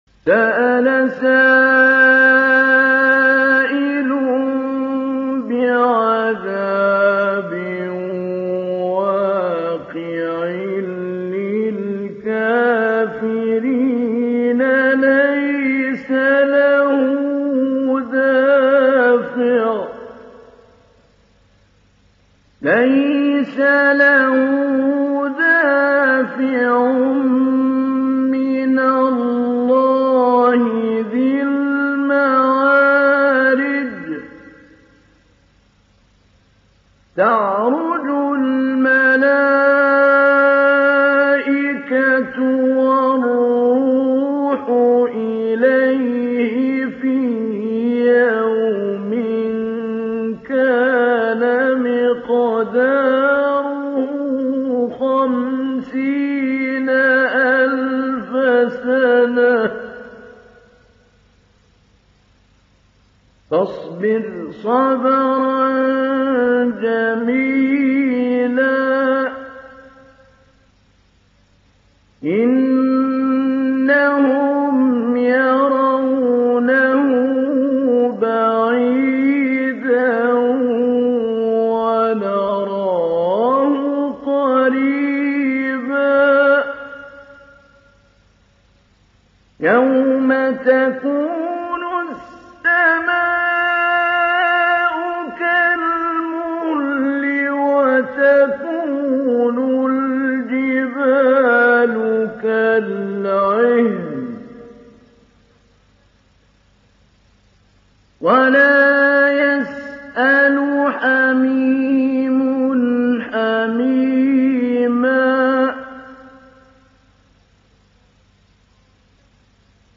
ডাউনলোড সূরা আল-মা‘আরেজ Mahmoud Ali Albanna Mujawwad